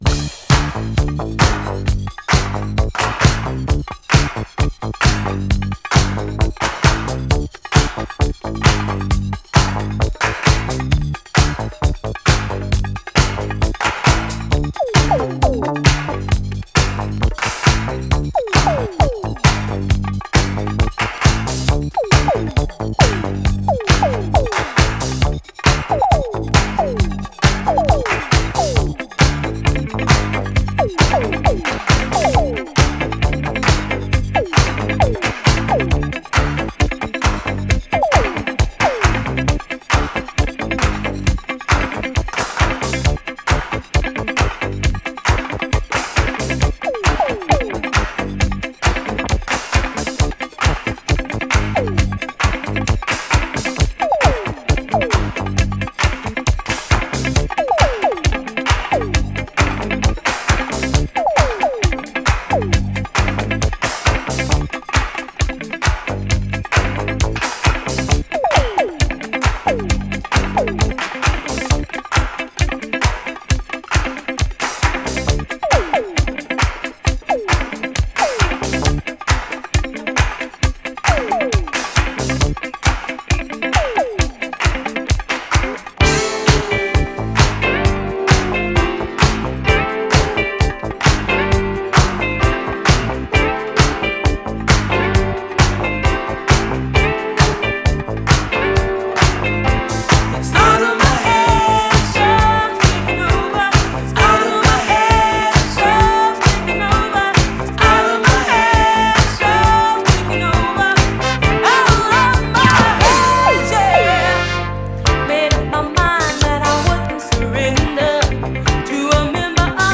CHICAGO's MODERN BOOGIE !!
グイッグイ迫るベースラインとピュンピュン音も抜群、女性シンガー
DISCO 12